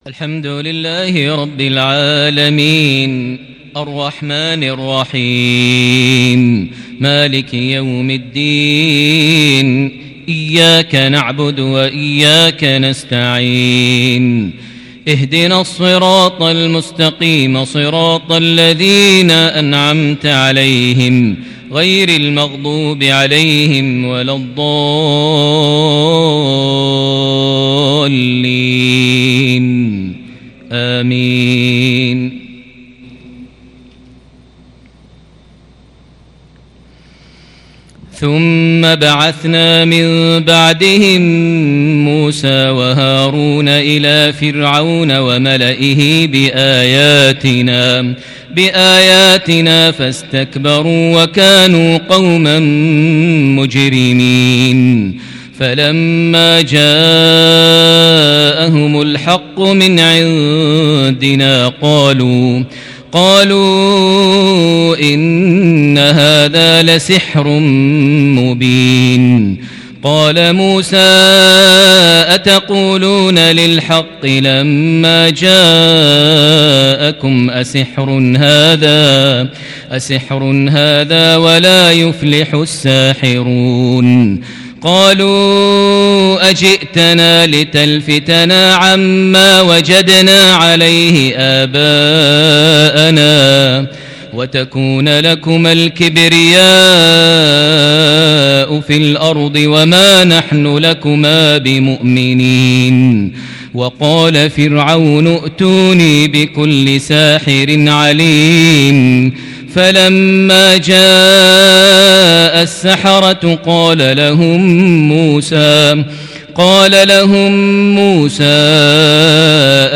جمال التلاوة وروعة الوصف لقصة موسى وفرعون من سورة يونس (74- 92) | عشاء يوم عاشوراء 10 محرم 1442هـ > 1442 هـ > الفروض - تلاوات ماهر المعيقلي